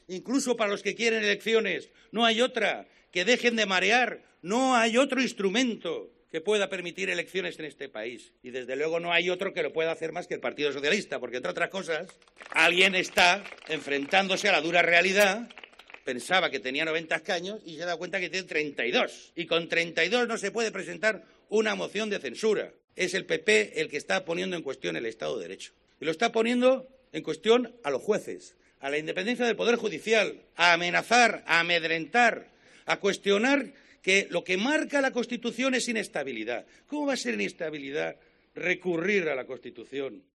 "Quien no tiene otra alternativa es España, porque nos han metido directamente en la ingobernabilidad", ha subrayado en un acto en la localidad valenciana de Torrent, donde ha hecho un llamamiento a Ciudadanos para que "deje de marear" con las elecciones, convencido de que la única posibilidad de ir a comicios es a través de la moción de censura.